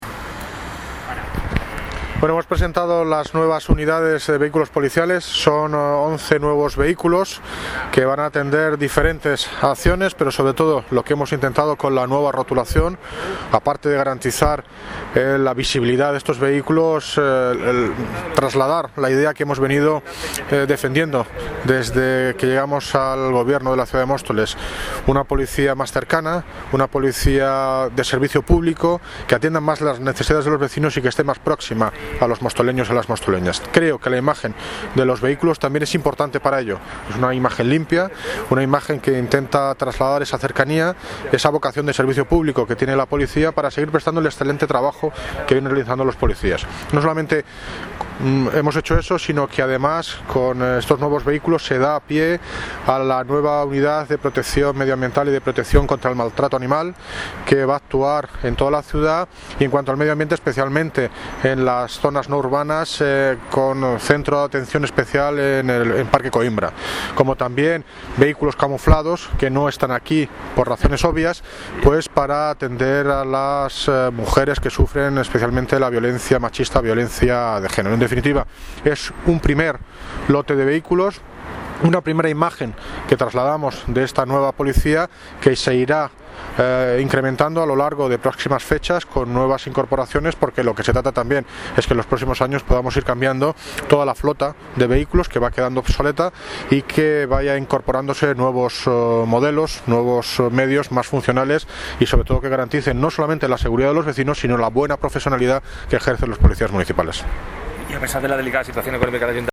Audio - David Lucas (Alcalde de Móstoles) sobre nuevos coches Policia Municipal